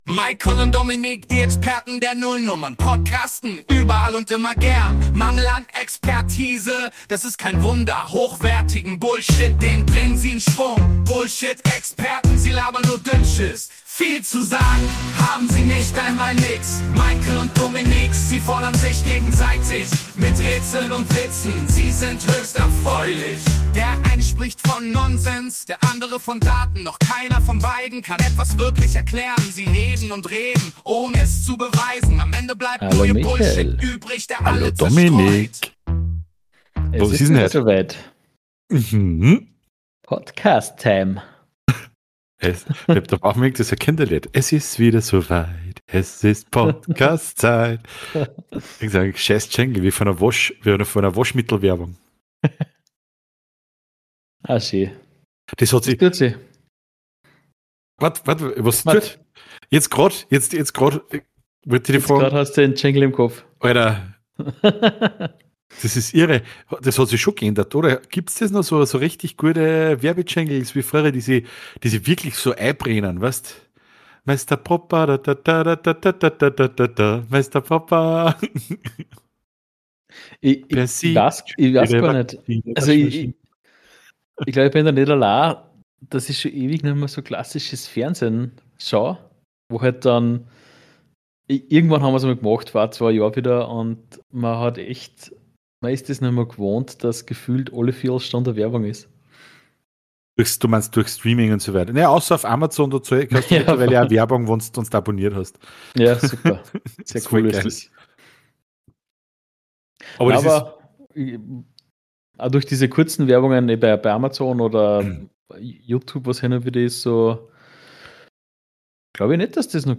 Singt mit uns gemeinsam die besten Jingles der 90er.